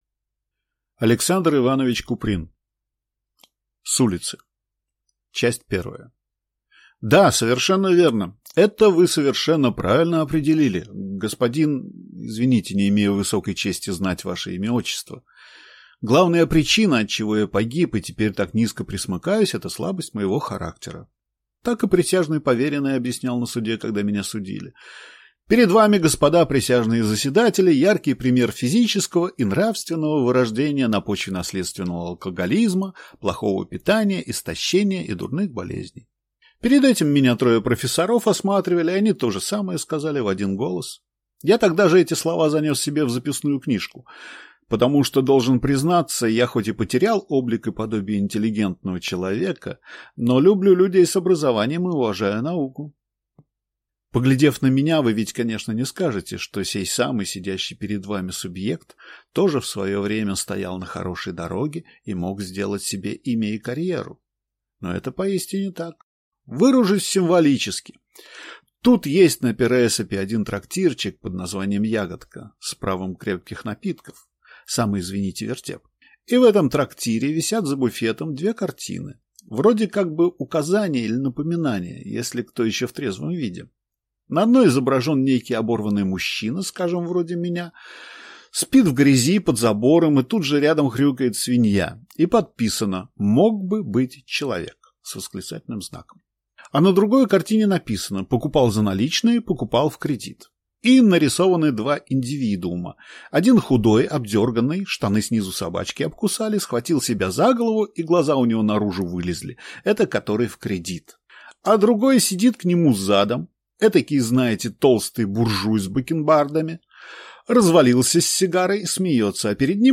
Аудиокнига С улицы | Библиотека аудиокниг